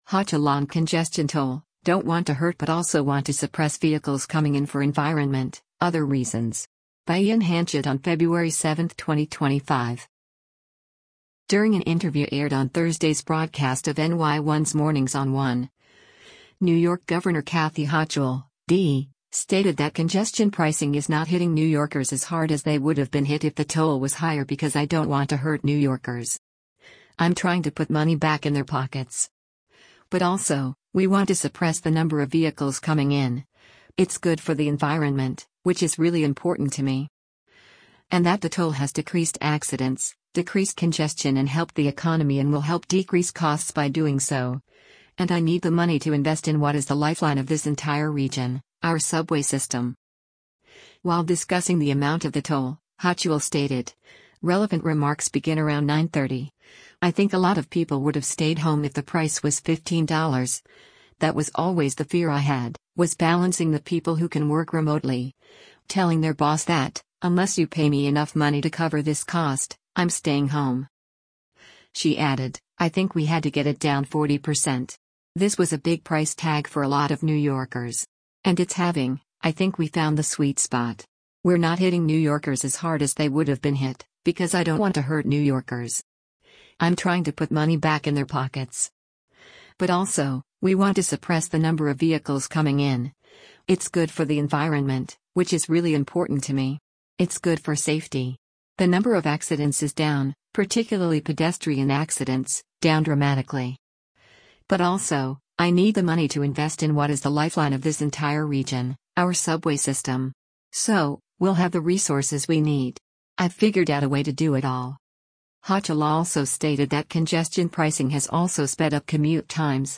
During an interview aired on Thursday’s broadcast of NY1’s “Mornings on 1,” New York Gov. Kathy Hochul (D) stated that congestion pricing is “not hitting New Yorkers as hard as they would have been hit” if the toll was higher “because I don’t want to hurt New Yorkers. I’m trying to put money back in their pockets. But also, we want to suppress the number of vehicles coming in, it’s good for the environment, which is really important to me.” And that the toll has decreased accidents, decreased congestion and helped the economy and will help decrease costs by doing so, and “I need the money to invest in what is the lifeline of this entire region, our subway system.”